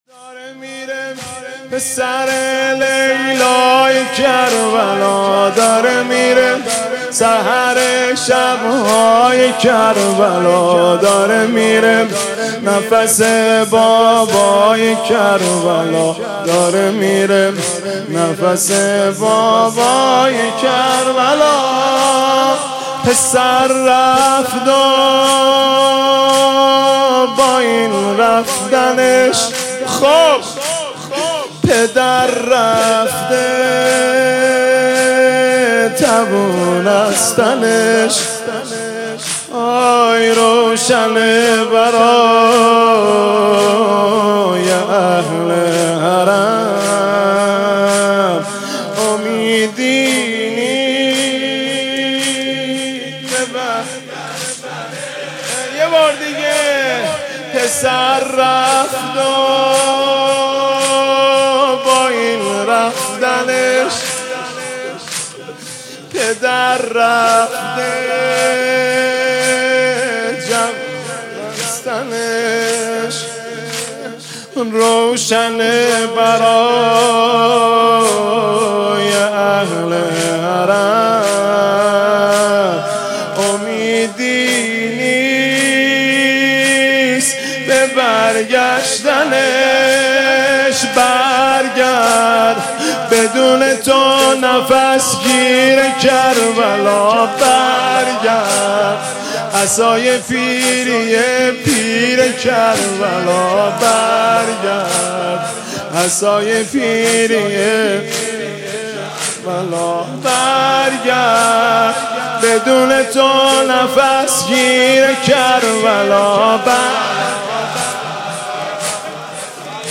• نوحه و مداحی
ویژه مراسم شب اول محرم الحرام ۱۴۴۴